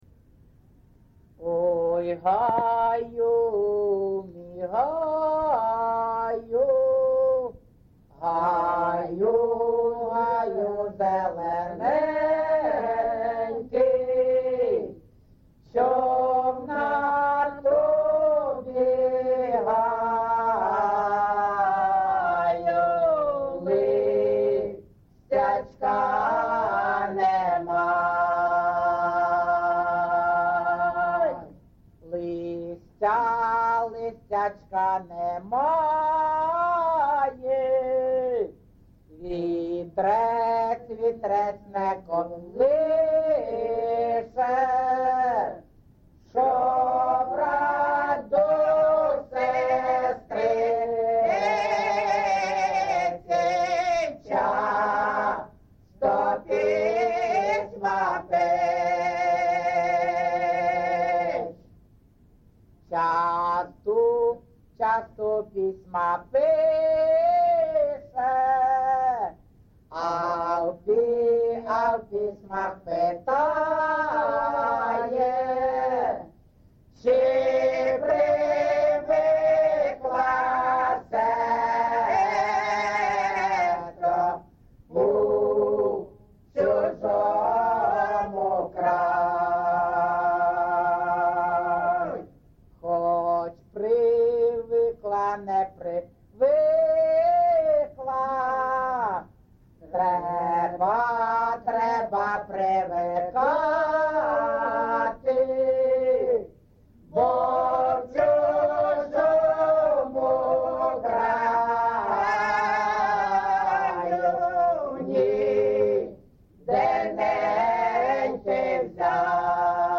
ЖанрПісні з особистого та родинного життя
Місце записус. Іскра (Андріївка-Клевцове), Великоновосілківський (Волноваський) район, Донецька обл., Україна, Слобожанщина